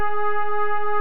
#1 I'm attaching two audio files from Pro Tools which were recorded from Kyma's output.
The second note is MIDI note #56 which is also not in my sequence.